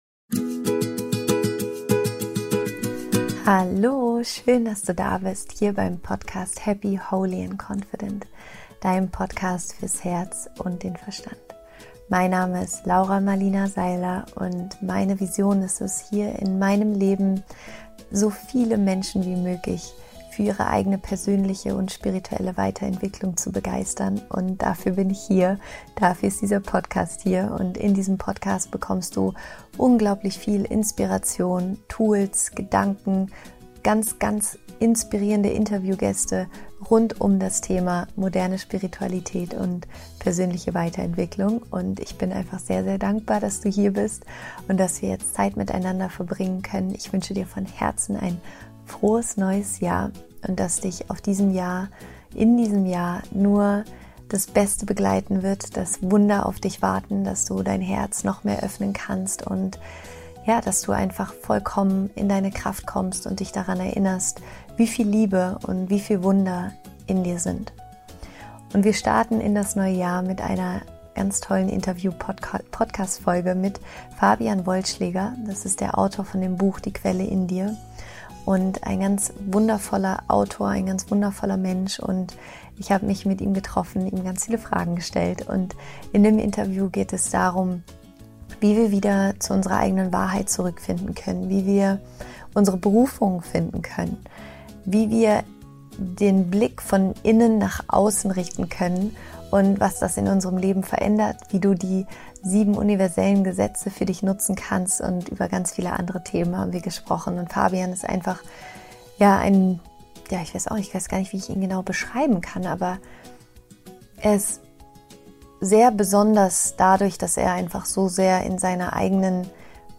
Es ist ein ganz wundervolles, inspirierendes Interview und einfach perfekt, um damit ins neue Jahr zu starten.